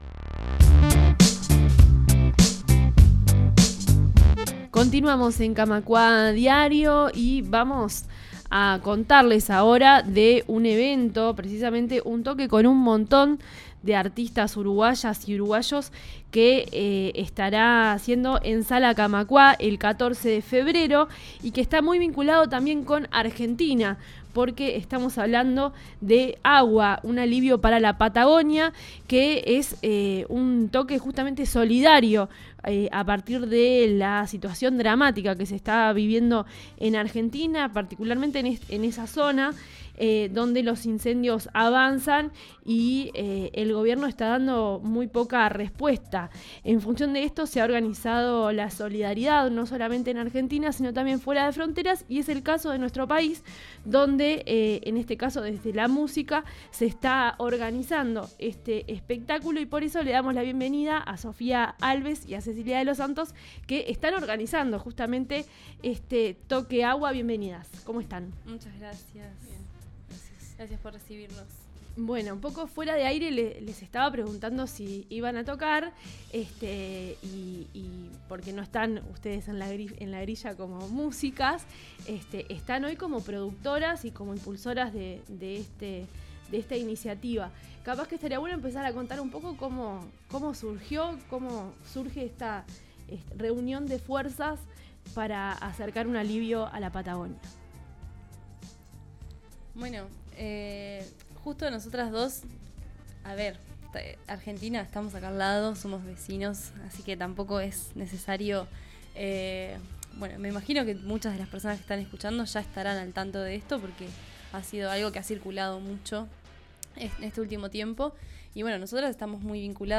Coloquio sobre la reforma laboral en Argentina